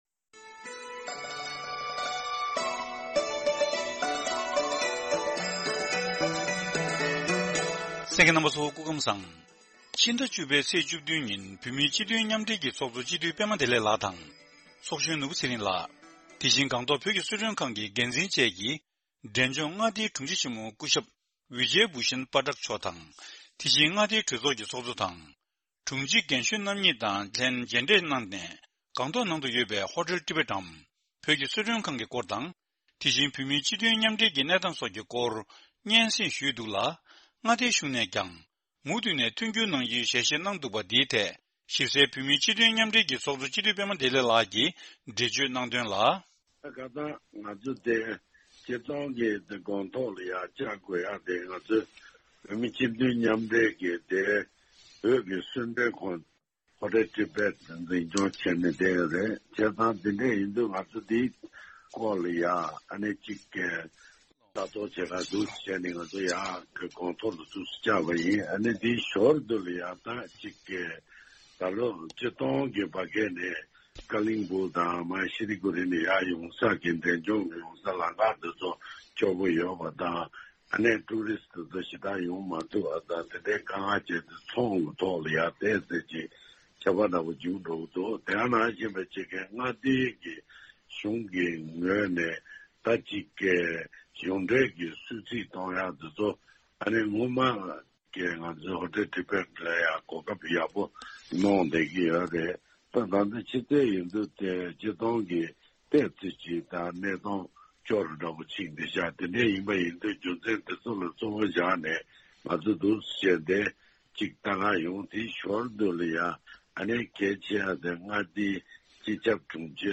བོད་སྐད་སྡེ་ཚན།